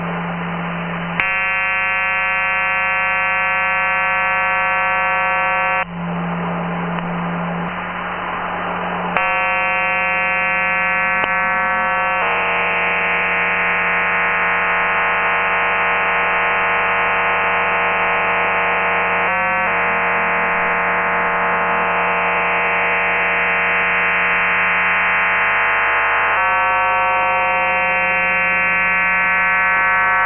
CIS 12-Tones
Криптомодем на сороковке. 7030кГц USB. (CIS 12-TONES)